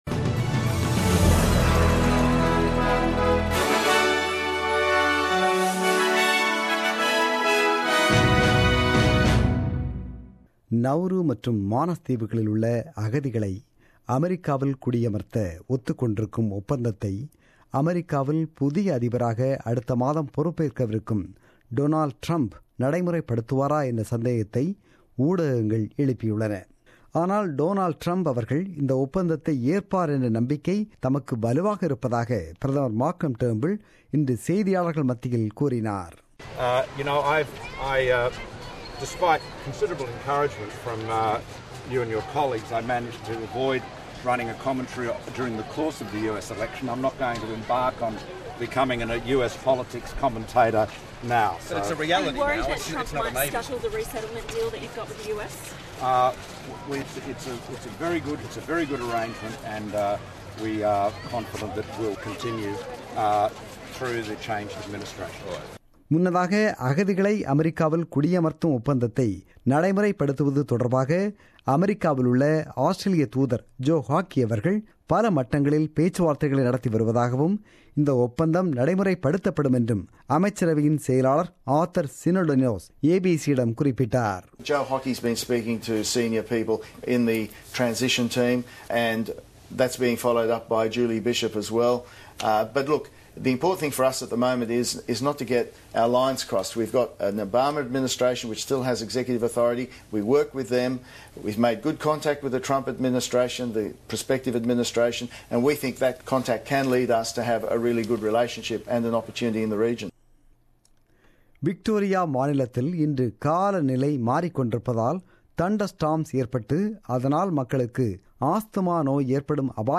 The news bulletin broadcasted on 4 December 2016 at 8pm.